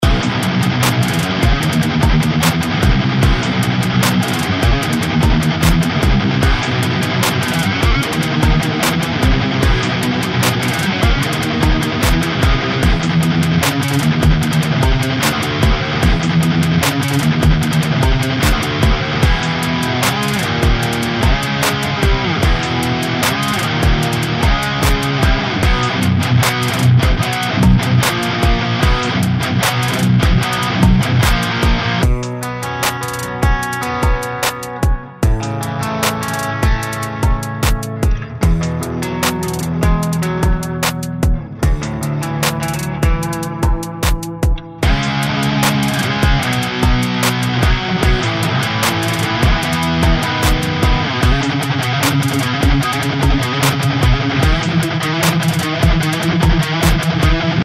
Packed with 37 meticulously crafted loops, this collection is a must-have for any producer looking to inject raw energy and aggression into their music.
From thunderous palm-muted riffs to blistering power chords, every sound is designed to cut through the mix with razor-sharp clarity and intensity.
Metal-Guitar-Rhythms-Vol-1.mp3